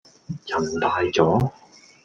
Голоса - Гонконгский 430